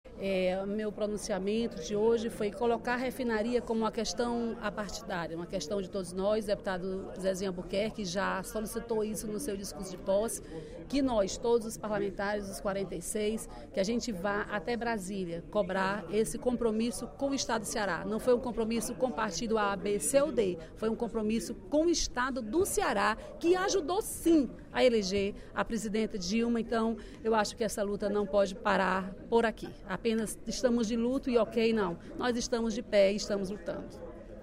Durante o primeiro expediente da sessão plenária desta quinta-feira (05/02), a deputada Dra. Silvana (PMDB) propôs uma mobilização apartidária em prol da refinaria do Estado.